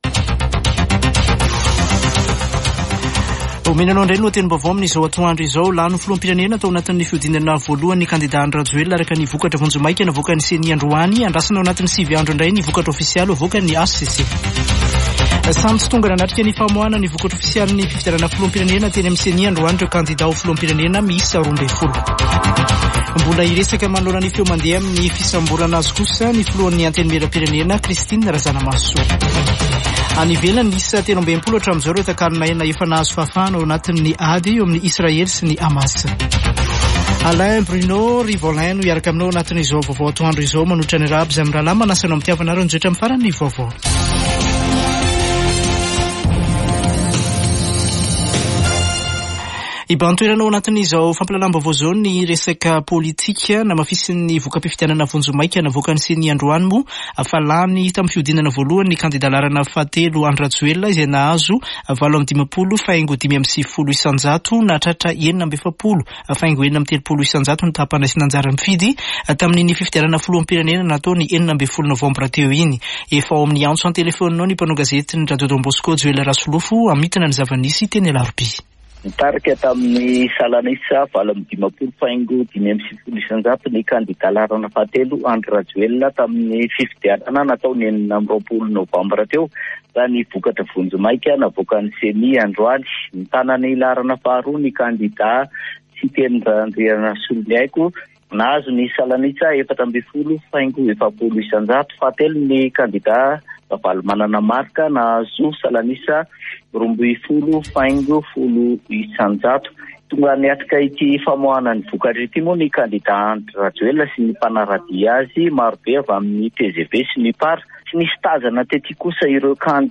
[Vaovao antoandro] Sabotsy 25 nôvambra 2023